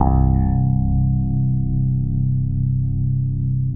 14BASS01  -R.wav